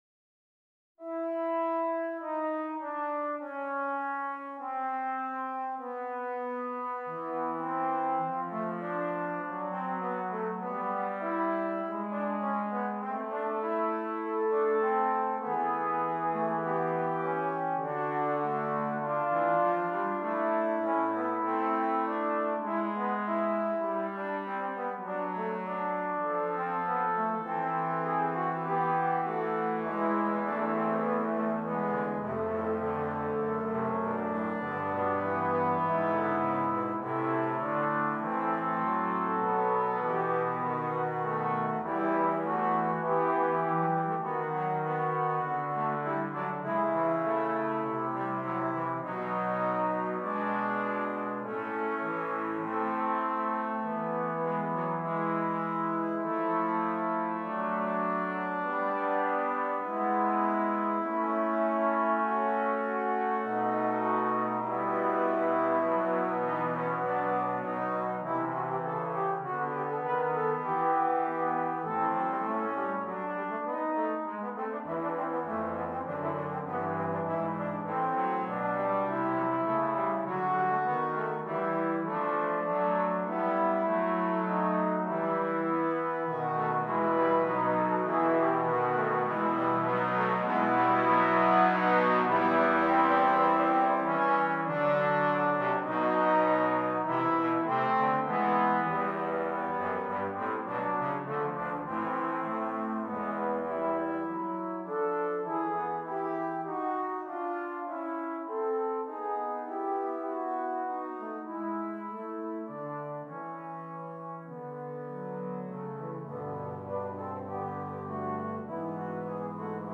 5 Trombones